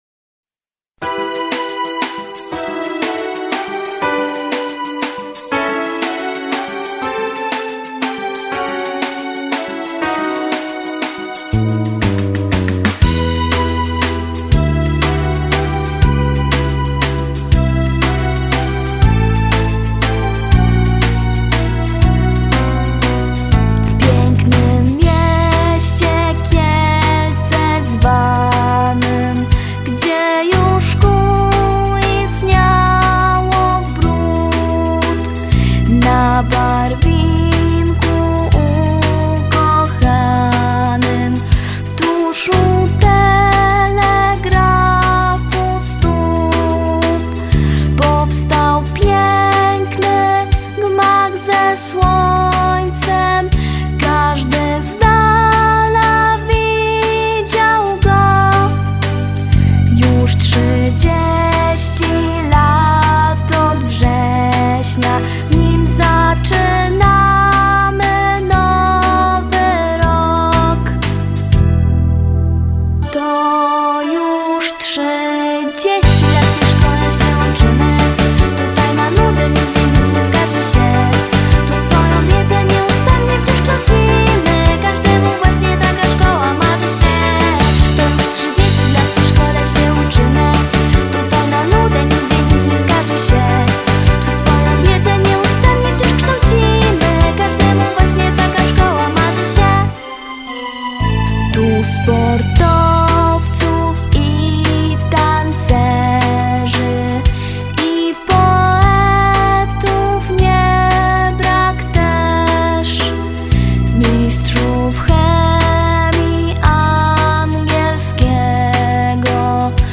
W dniu 13 października 2017 r. uroczyście świętowaliśmy Jubileusz 30-lecia Szkoły Podstawowej nr 32 im. Janusza Kusocińskiego w Kielcach.
Po części oficjalnej przedstawiono program artystyczny.